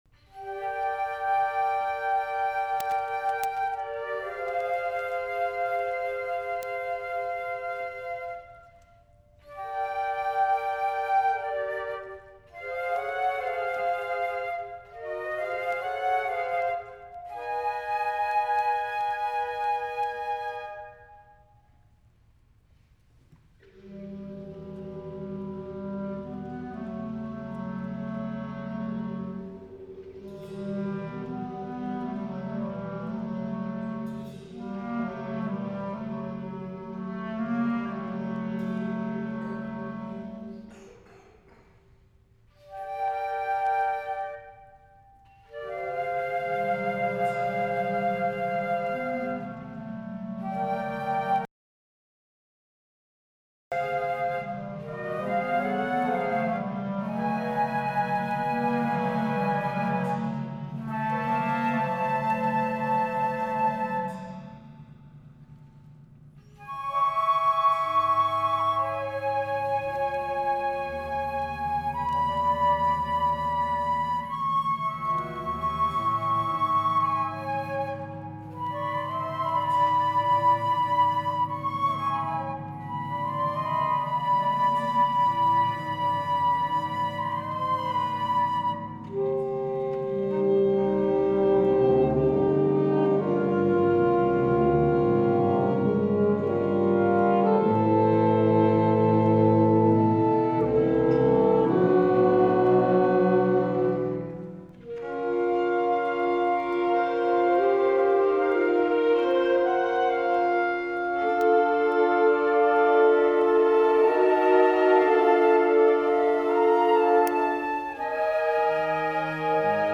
Genre: Band
Timpani
Percussion 1 (vibraphone, bells, crash cymbals, triangle)
Percussion 2 (tam-tam, snare drum, bass drum)